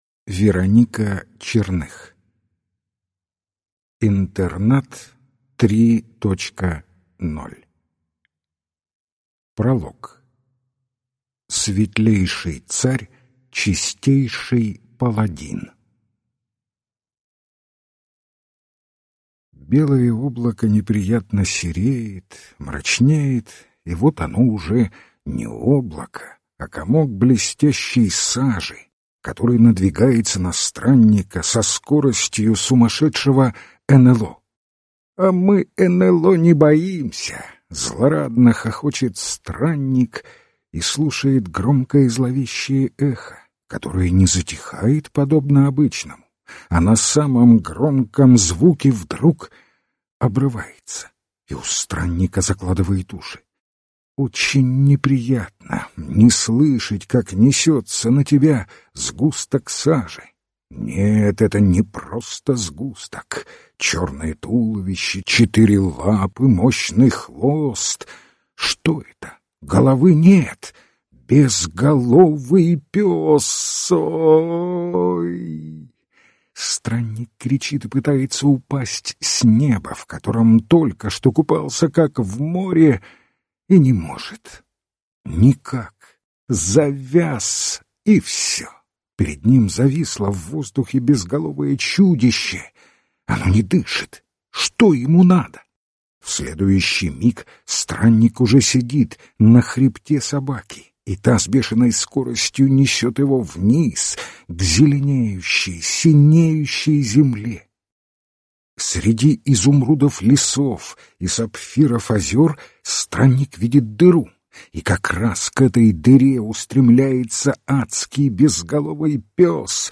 Аудиокнига INTERNAT 3.0 | Библиотека аудиокниг
Прослушать и бесплатно скачать фрагмент аудиокниги